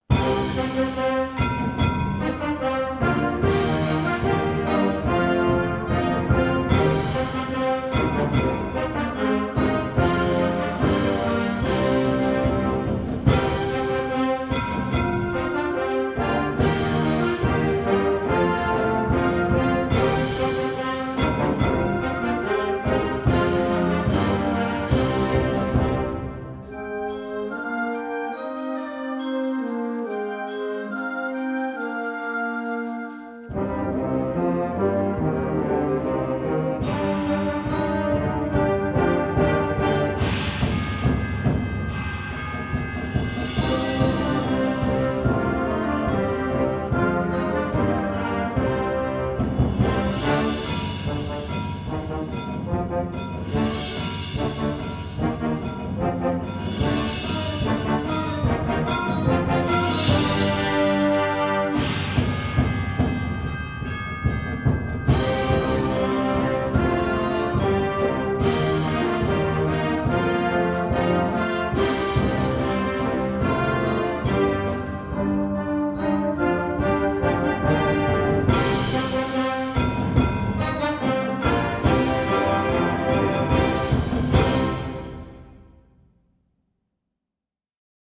Gattung: Konzertant
Besetzung: Blasorchester